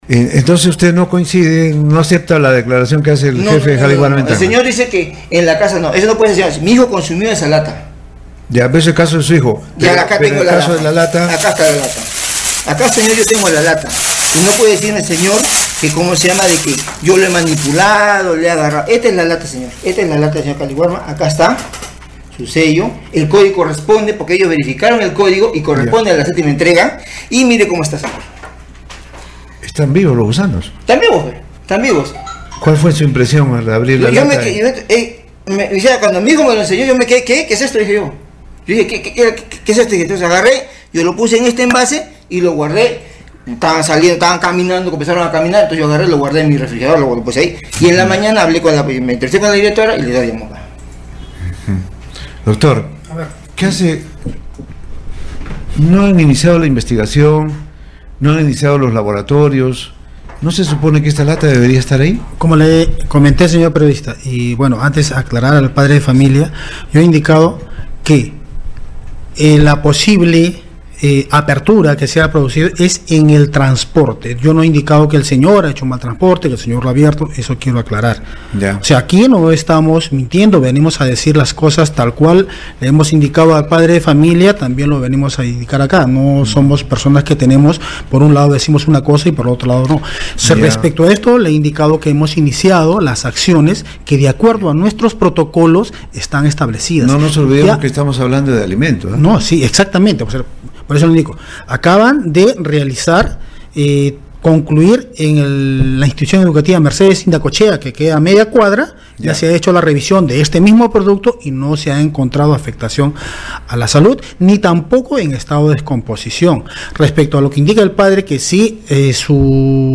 El padre de familia denunciante al escuchar al funcionario en Radio Uno decidió llegar a cabina con el producto en mano a fin de ratificar la acusación afirmando que la lata fue abierta el domingo 24.